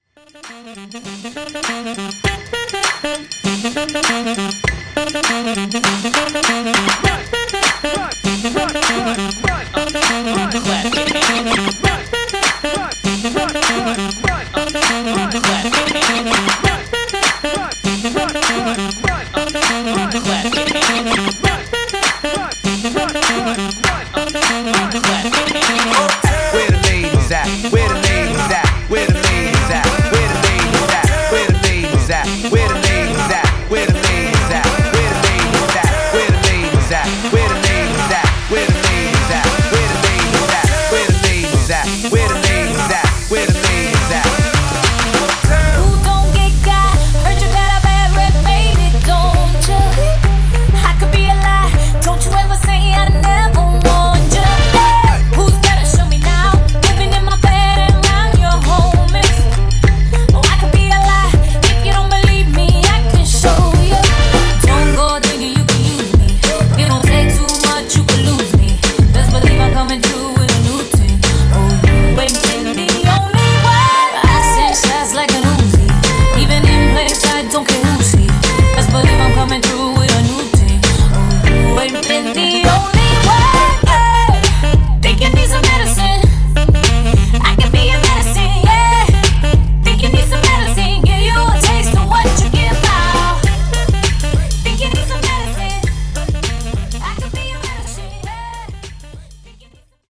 Party Breaks Starter)Date Added